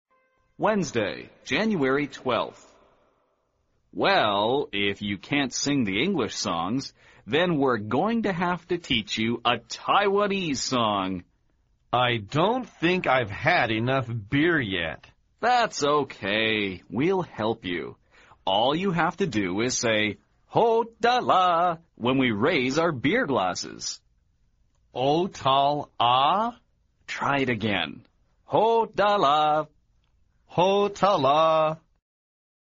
位置：首页 > 英语听力 > 美语|美国英语|美式英语 > 美语会话实录